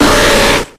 Audio / SE / Cries / SLOWBRO.ogg